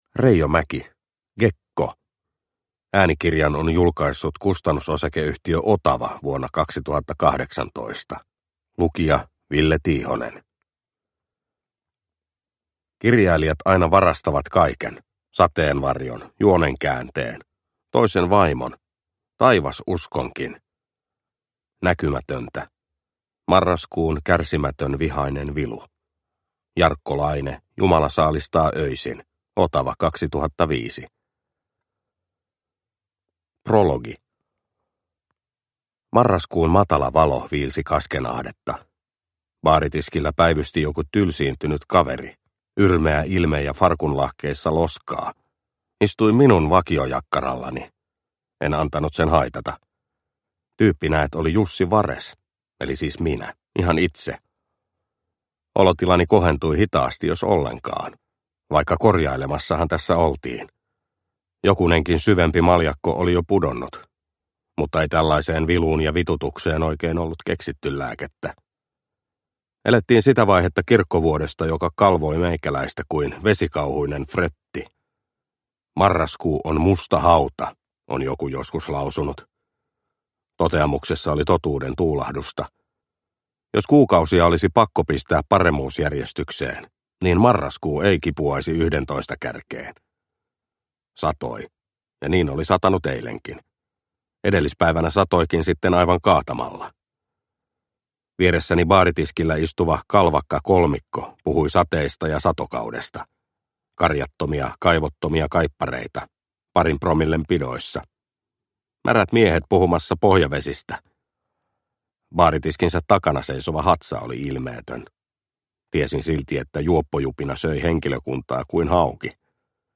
Gekko – Ljudbok – Laddas ner